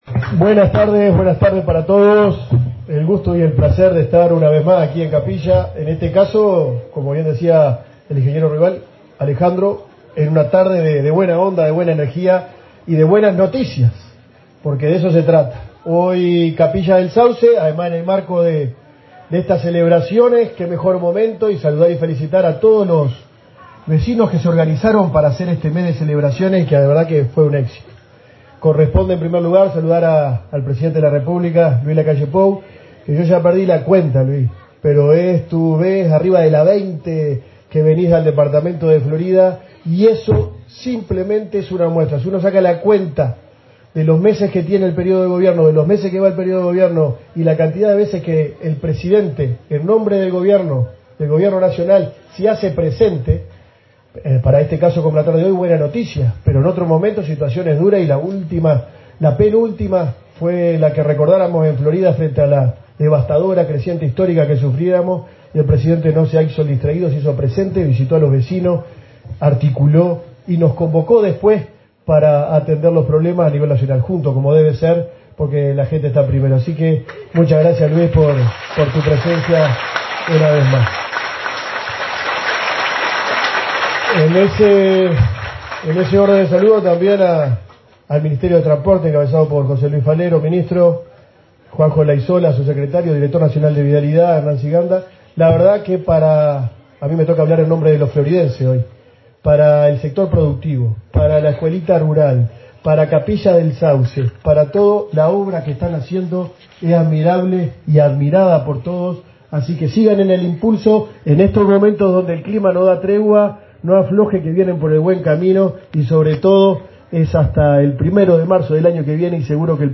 Acto de inauguración de obras en la ruta 6 de Capilla del Sauce
Acto de inauguración de obras en la ruta 6 de Capilla del Sauce 10/06/2024 Compartir Facebook X Copiar enlace WhatsApp LinkedIn El Ministerio de Transporte y Obras Públicas (MTOP) inauguró, este 10 de junio, obras en el tramo de ruta 6 en Capilla del Sauce, Florida, con la presencia del presidente de la República, Luis Lacalle Pou. En el evento participaron el ministro José Luis Falero y el intendente departamental, Guillermo López.